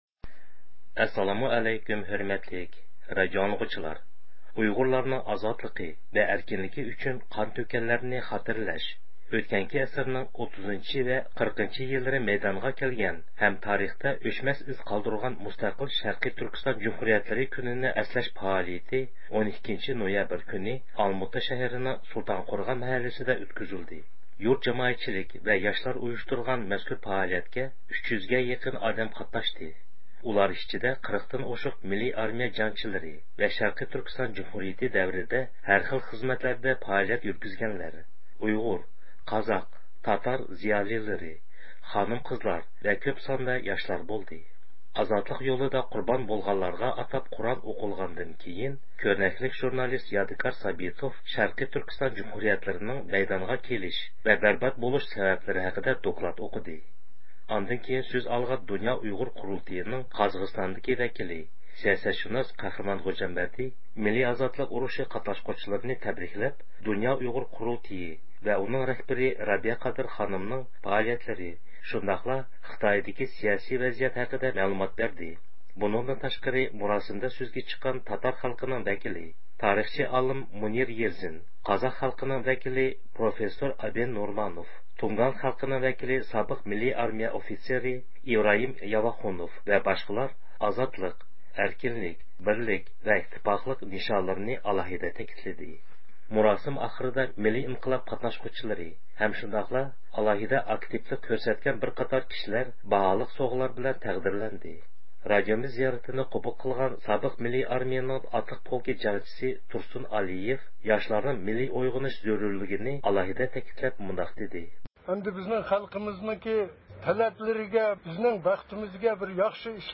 ئۇيغۇرلارنىڭ ئازادلىقى ۋە ئەركىنلىكى ئۈچۈن قان تۆككەنلەرنى خاتىرىلەش، ئۆتكەنكى ئەسىرنىڭ 30-ۋە 40-يىللىرى مەيدانغا كەلگەن ھەم تارىختا ئۆچمەس ئىز قالدۇرغان مۇستەقىل شەرقىي تۈركىستان جۇمھۇرىيەتلىرى كۈنىنى ئەسلەش پائالىيىتى 12-نويابىر كۈنى ئالماتا شەھىرىنىڭ سۇلتانقورغان مەھەللىسىدە ئۆتكۈزۈلدى.